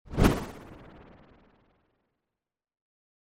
Fire_wall_brick_val.mp3